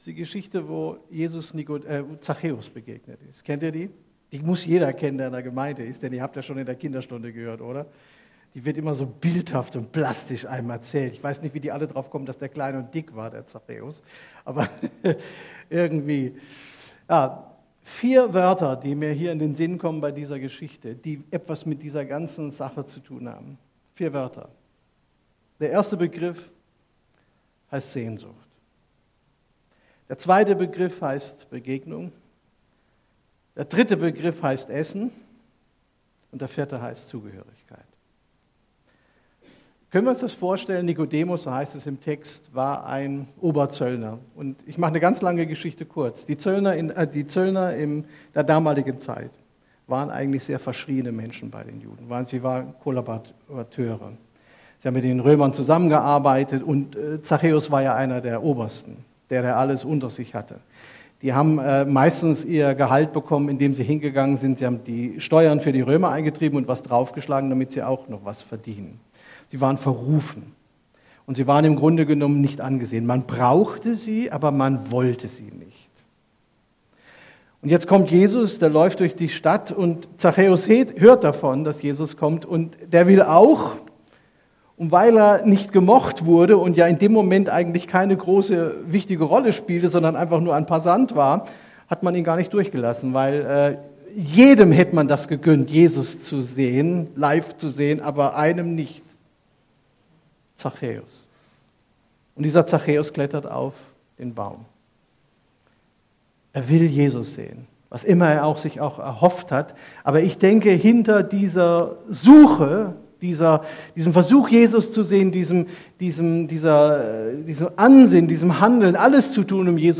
Predigt vom 22.05.2022 › ETG-Ludwigsburg